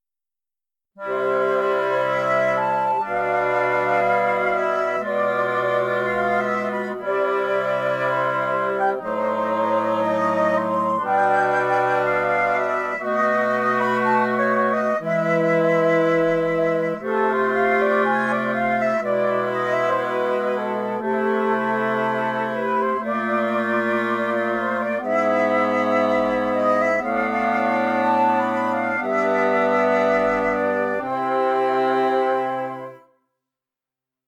Ab-Dictat-Classica-Antiga-Cobla-Arrel.mp3